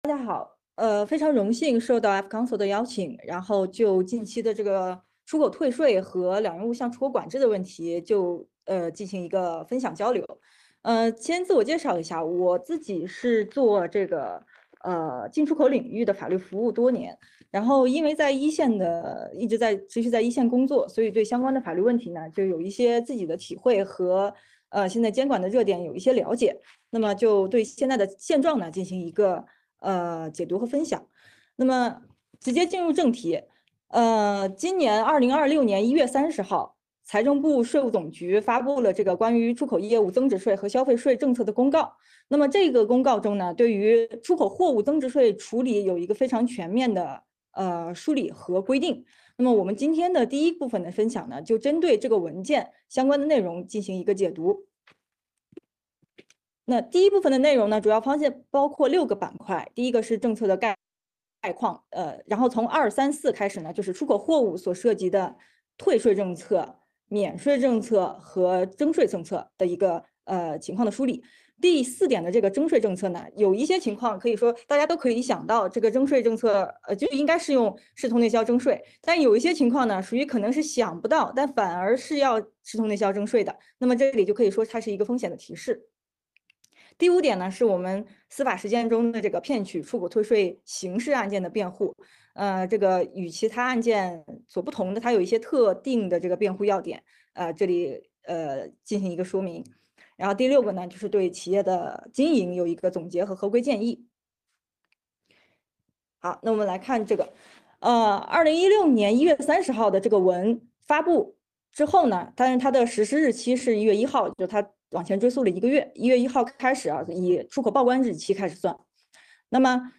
视频会议
14 ：00 主持人开场
15 ：15 互动问答